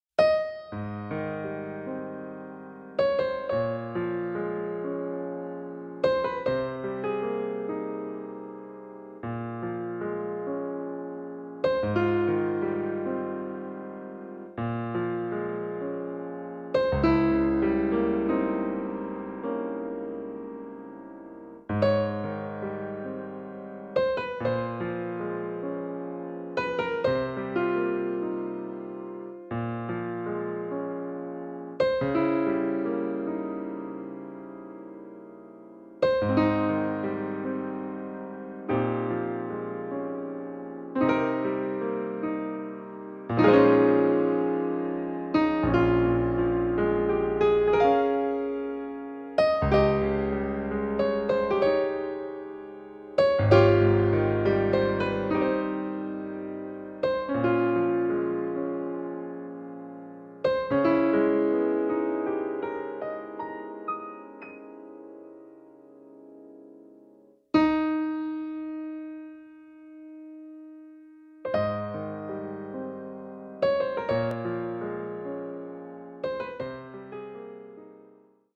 Some proof in this piano solo where feelings explain.